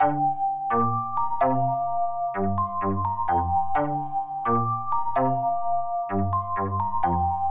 星形钟环路
Tag: 128 bpm Pop Loops Bells Loops 1.26 MB wav Key : G Reason